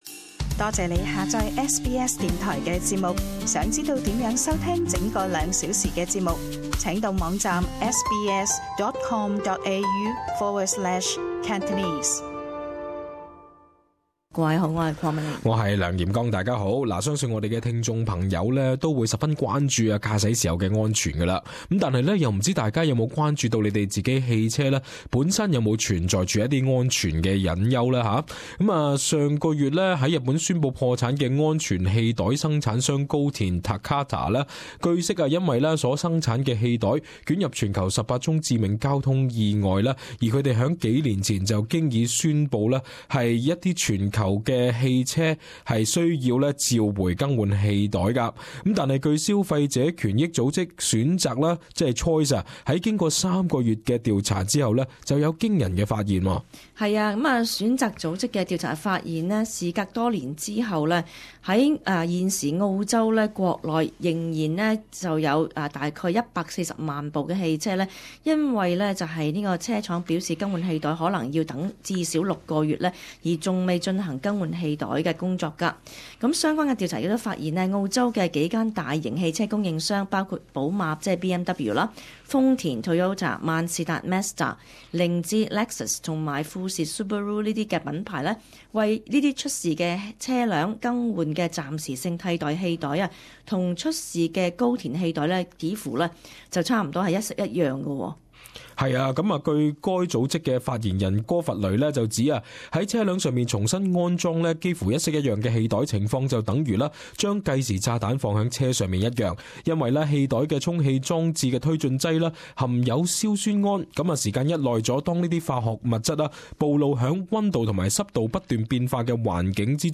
【時事報導】選擇:澳洲約百四萬部車正置身氣袋爆炸風險